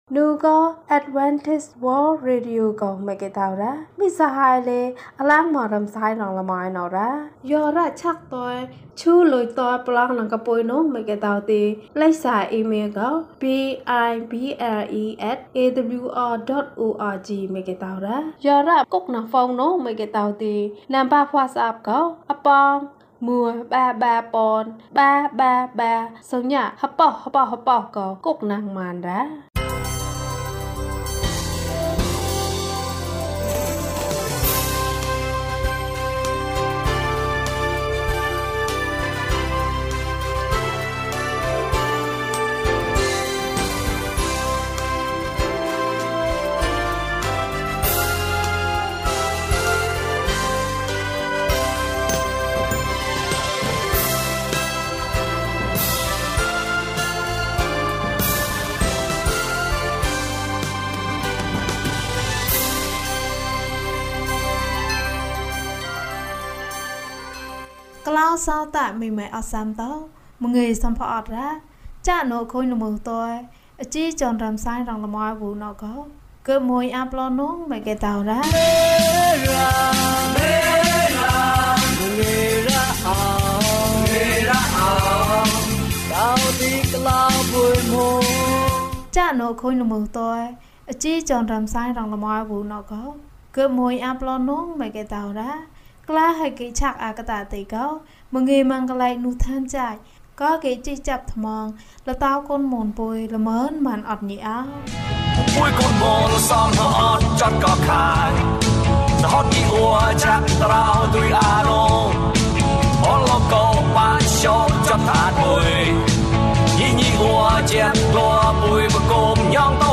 ကောင်လေးက ကမ်းခြေမှာ။ ကျန်းမာခြင်းအကြောင်းအရာ။ ဓမ္မသီချင်း။ တရားဒေသနာ။